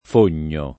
fogno [ f 1 n’n’o ] s. m.